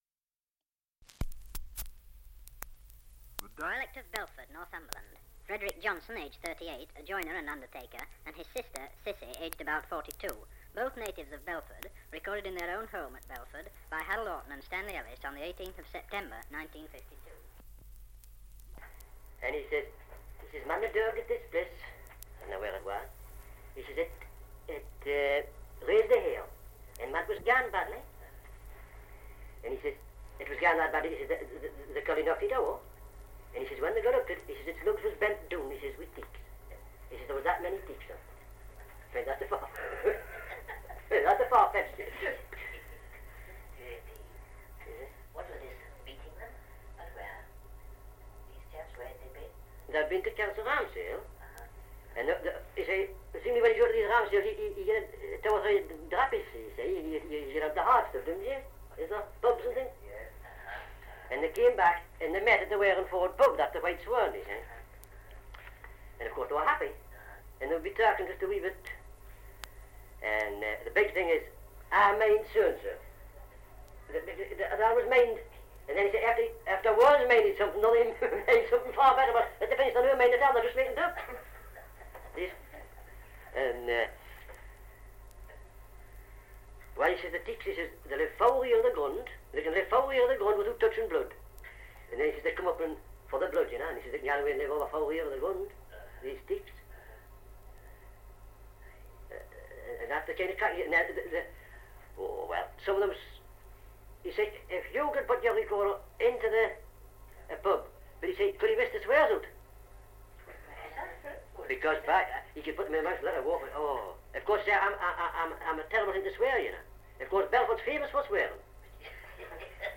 1 - Dialect recording in Belford, Northumberland
78 r.p.m., cellulose nitrate on aluminium
English Language - Dialects